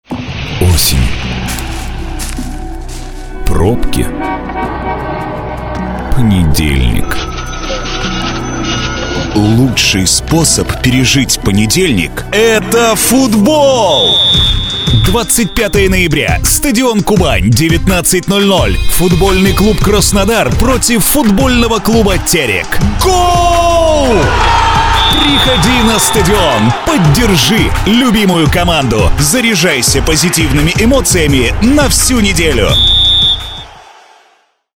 ролик матча для футбольного клуба "Краснодар"